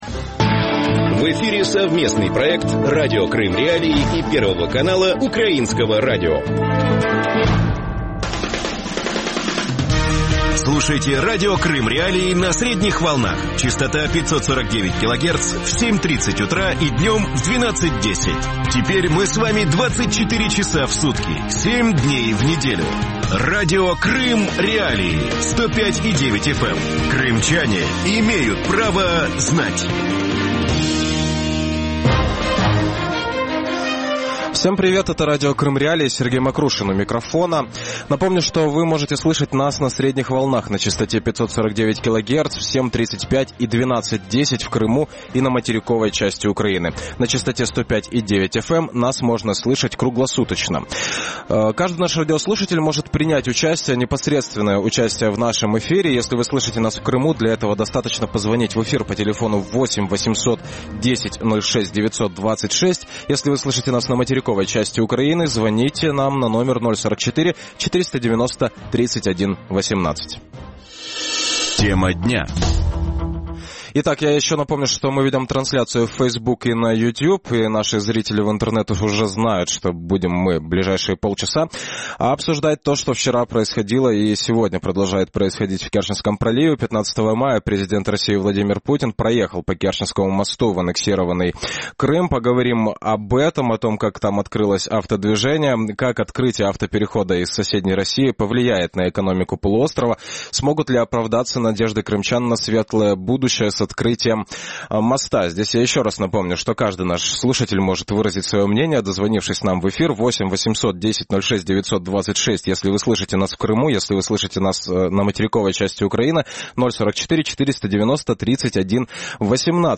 Как поможет жителям аннексированного Крыма Керченский мост? Как открытие автоперехода из соседней России повлияет на экономику полуострова? Смогут ли оправдаться надежды крымчан на «светлое будущее» с открытием моста? Гости эфира